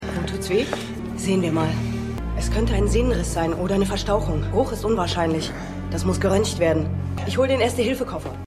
Synchronstudio: Interopa Film GmbH [Berlin]
24_2x17_CTUSanitaeterinZoltan.mp3